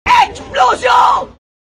Play, download and share …Explosion ! Macron original sound button!!!!